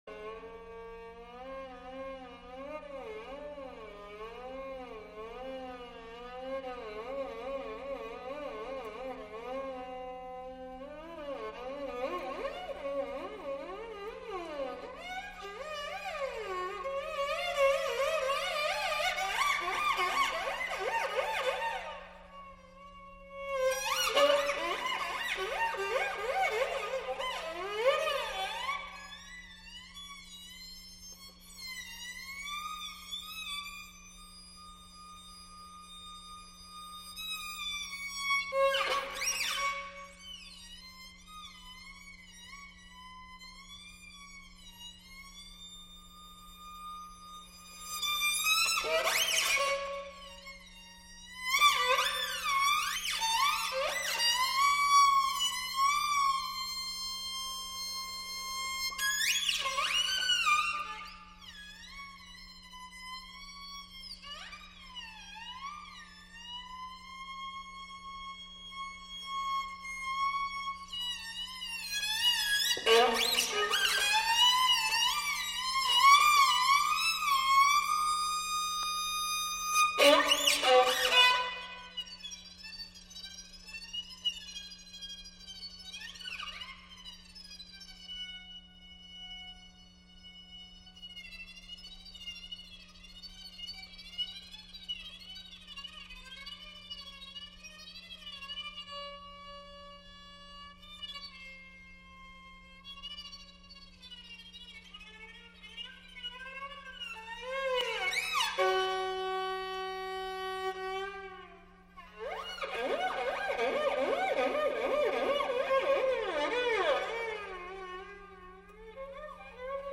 The interview is there - 5 min in